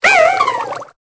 Cri de Viskuse dans Pokémon Épée et Bouclier.